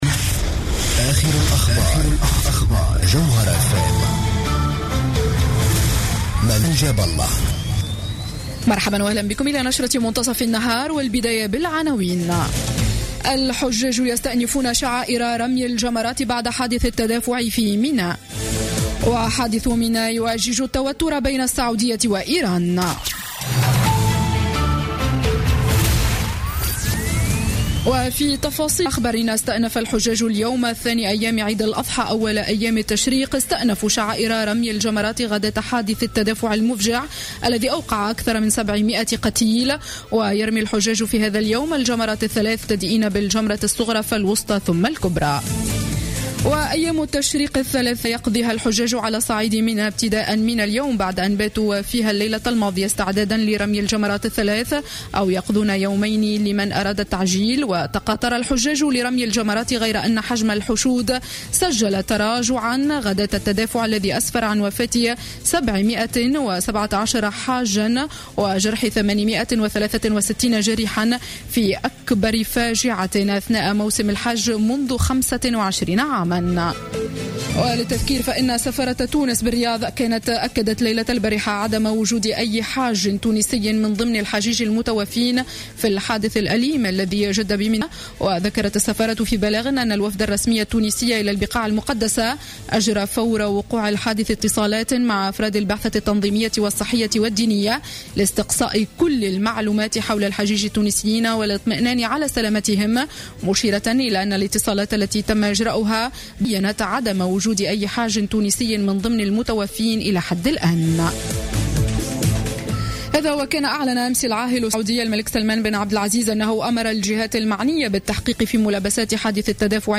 نشرة أخبار منتصف النهار ليوم الجمعة 25 سبتمبر 2015